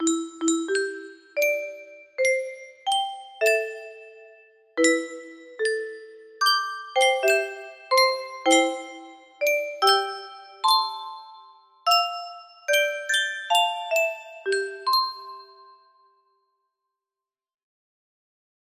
Stars music box melody